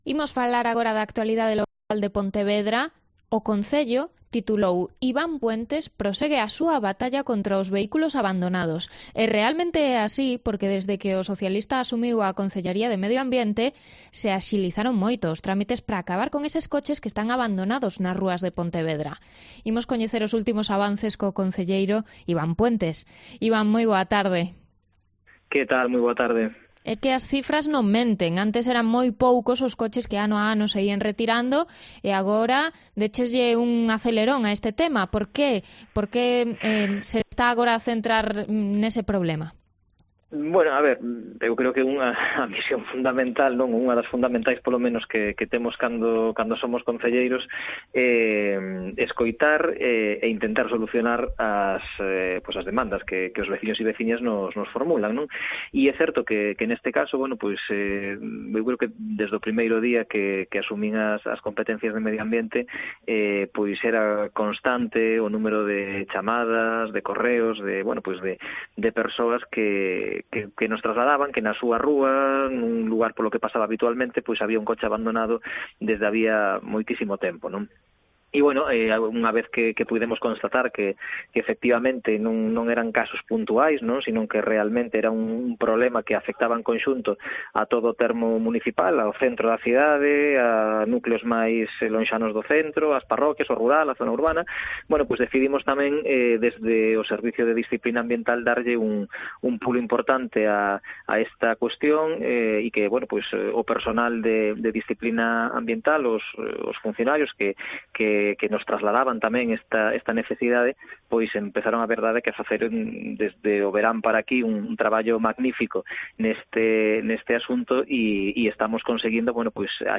El concejal de Medio Natural de Pontevedra, Iván Puentes, explica los pasos a dar desde que se recibe el aviso hasta que se puede desguazar un vehículo abandonado.
Entrevista sobre las últimas retiradas de vehículos abandonados en las calles de Pontevedra